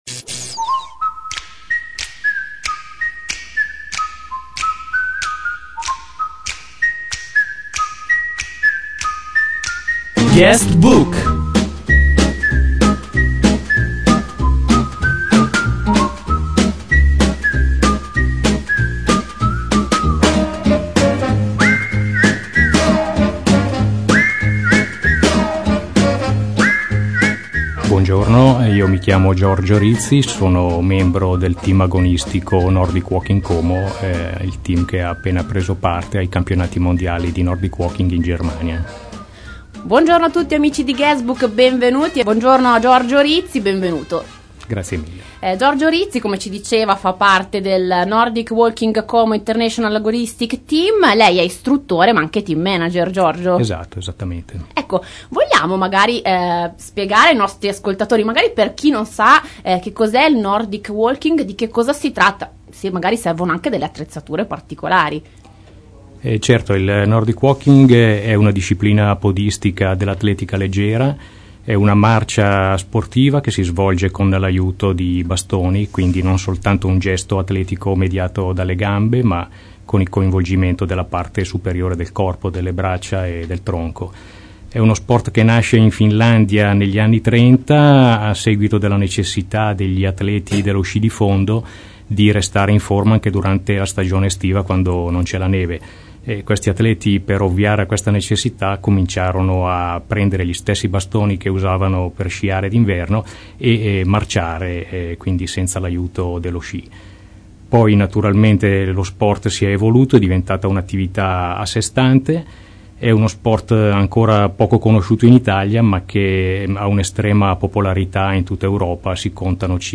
Ecco la traccia audio di una nostra intervista radiofonica.